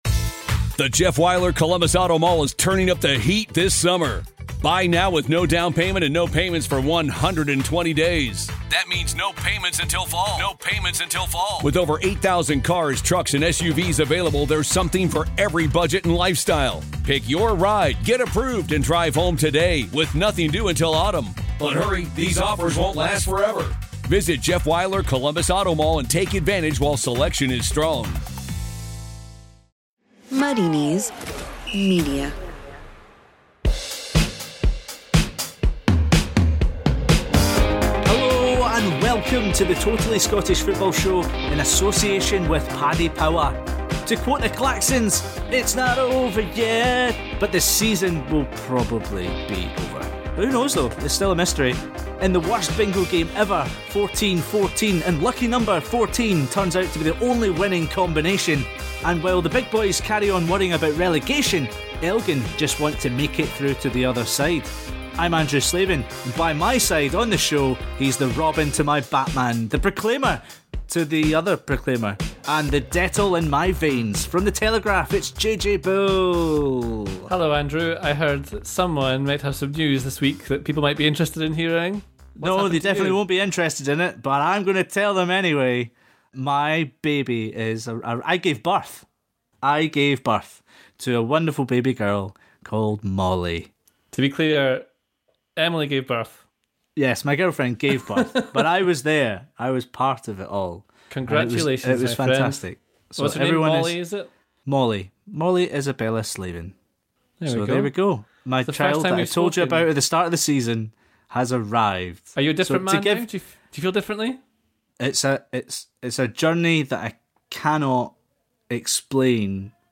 Gordon Strachan is this week's special guest as the team look back on the week that saw Dundee declared free from any wrongdoing, the League Two teams made their voices heard on reconstruction and Rangers continue to shout about their "dossier of evidence".